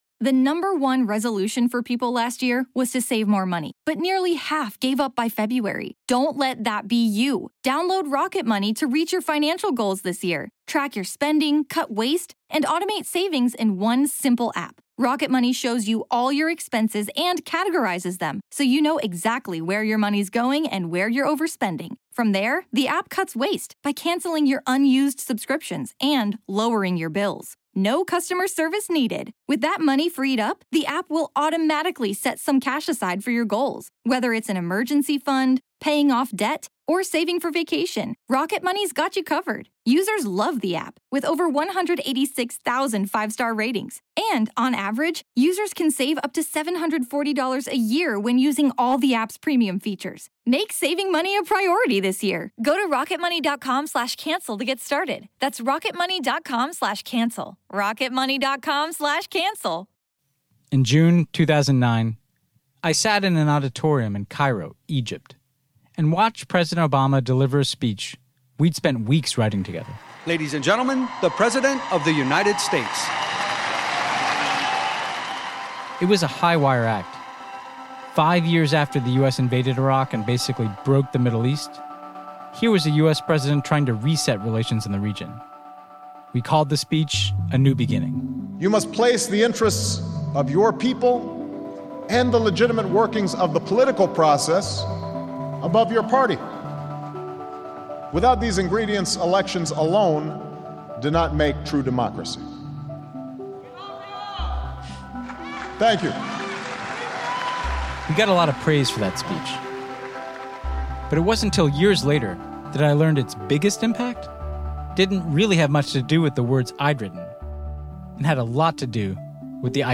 Then he talks to leading progressives in Congress and journalists about a blueprint to end the cycle of war.